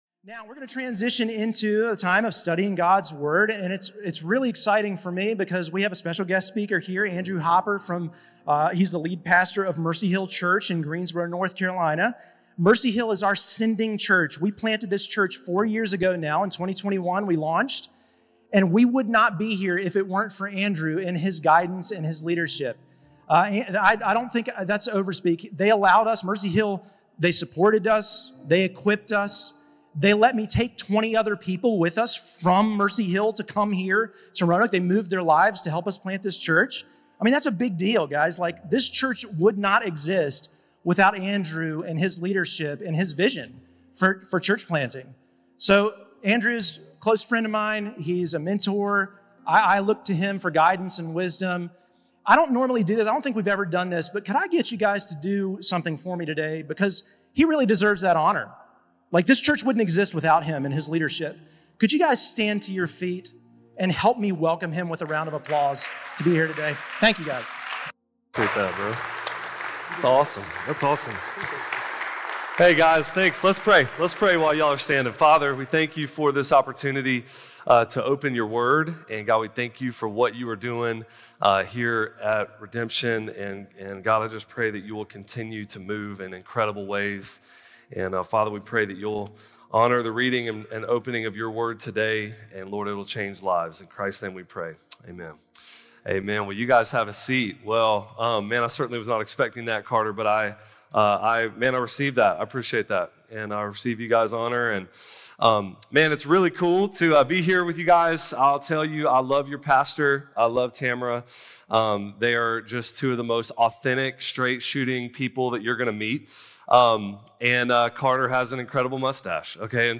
Today we had the honor of hearing from guest speaker